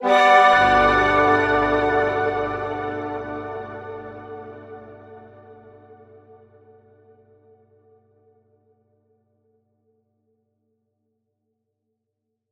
Chords_Gmaj_01.wav